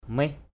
/mɪh/ mih m{H [Cam M] (t.) chắc chắn = certes. certainly. hu mih h~% m{H chắc chắn được, chắc chắn có = oui vraiment. yes really. thaoh mih _E<H m{H...
mih.mp3